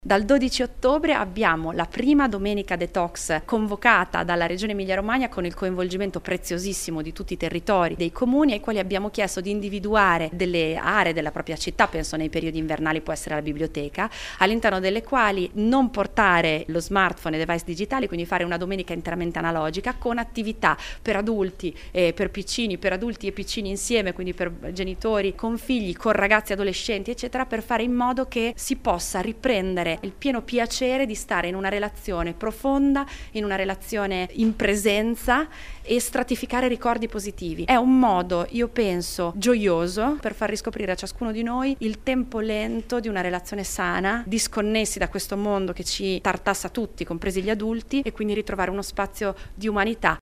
Lo ha annunciato ieri l’assessora al Welfare, Scuola, Politiche per l’infanzia e Terzo settore, Isabella Conti: